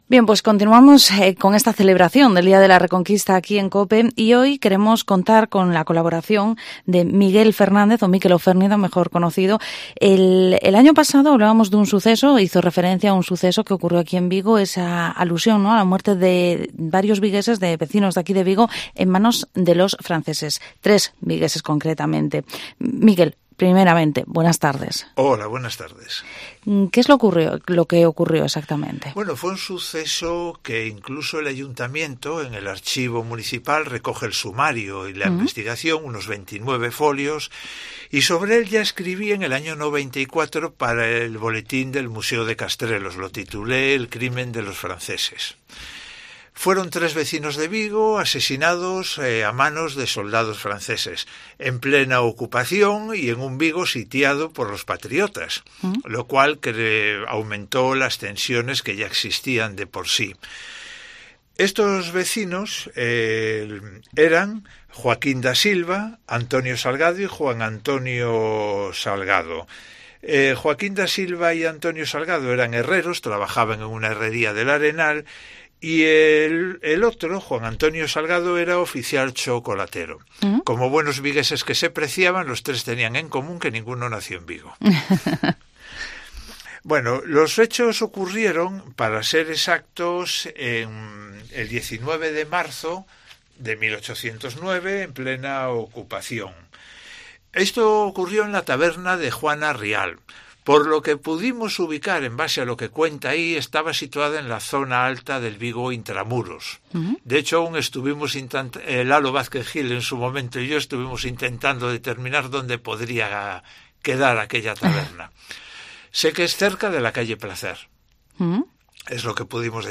Vigo Entrevista Tres vigueses murieron a manos de los franceses en 1809.